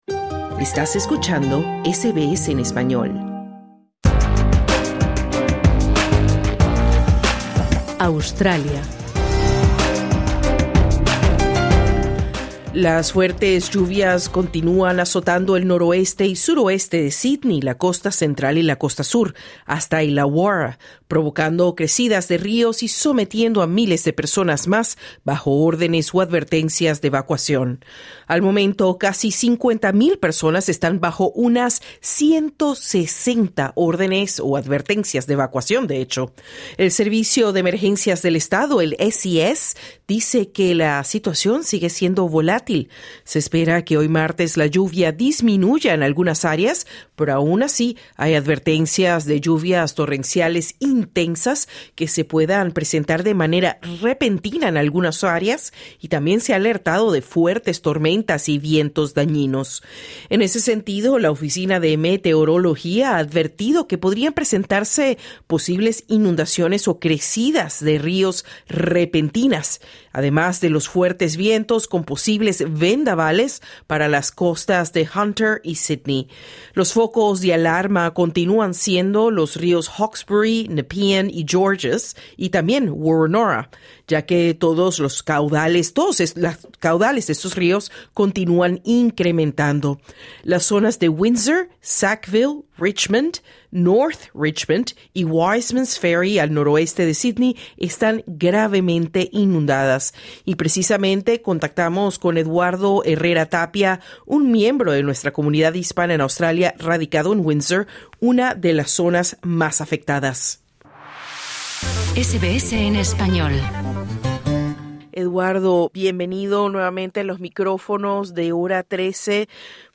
Los daños y la magnitud de las recientes inundaciones en Nueva Gales del Sur están rompiendo récords históricos y dejando a decenas de miles de personas sin hogares. SBS Spanish conversó con un inmigrante ecuatoriano que está tratando de superar el impacto de las recurrentes inundaciones por tercera vez en menos de dos años.